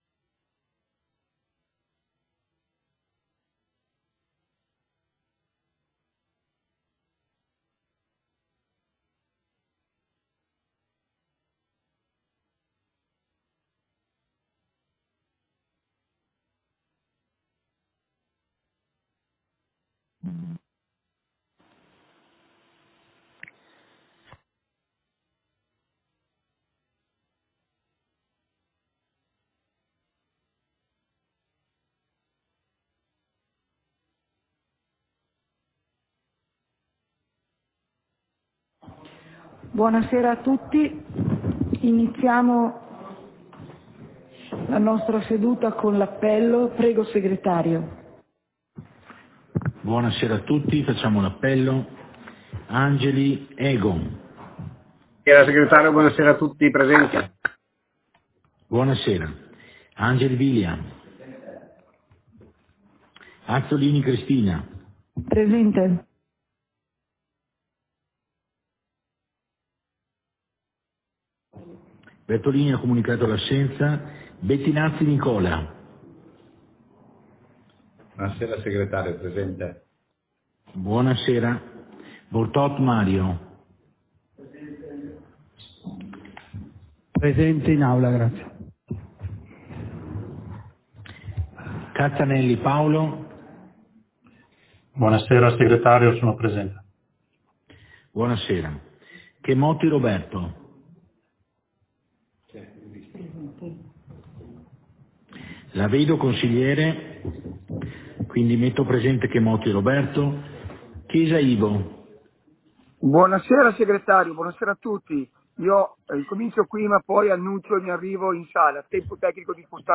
Seduta del consiglio comunale - 19.07.2022